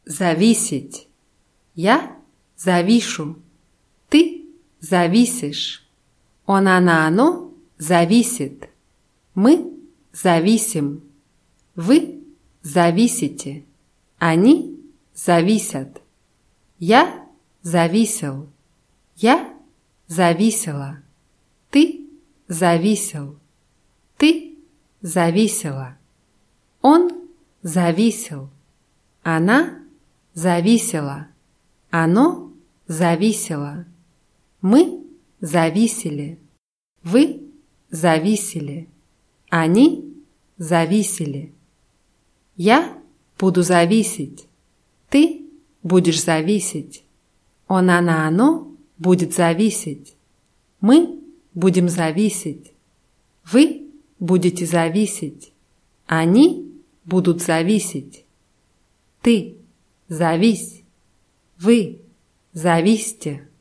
зависеть [zawʲíßʲitʲ]